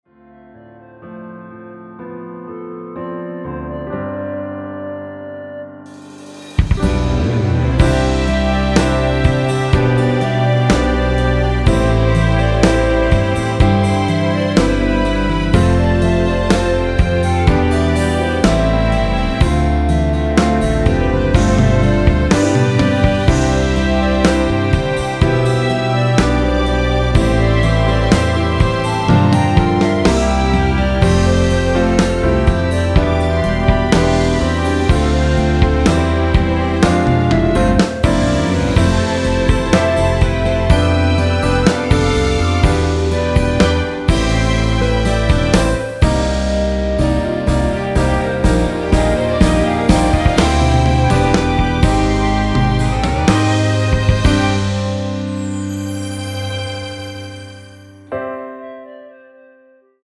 원키에서(-1)내린 멜로디 포함된 1절후 후렴으로 진행 되는 MR입니다.(본문 가사 확인)
◈ 곡명 옆 (-1)은 반음 내림, (+1)은 반음 올림 입니다.
앞부분30초, 뒷부분30초씩 편집해서 올려 드리고 있습니다.
중간에 음이 끈어지고 다시 나오는 이유는